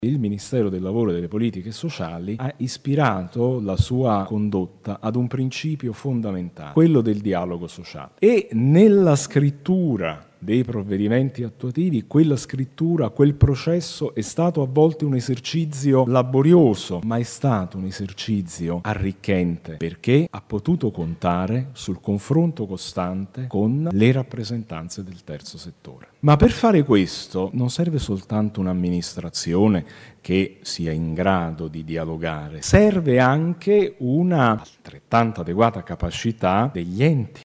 I concetti di libertà, democrazia e partecipazione – parole chiave dell’associazionismo e più in generale del Terzo settore – hanno guidato sia la stesura delle disposizioni del Codice del Terzo settore, ma soprattutto la riflessione successiva, quando le associazioni hanno dovuto adeguare i propri statuti alla nuova cornice normativa. Queste le considerazioni di Alessandro Lombardi, Direttore Generale Terzo Settore del Ministero del Lavoro e delle Politiche Sociali.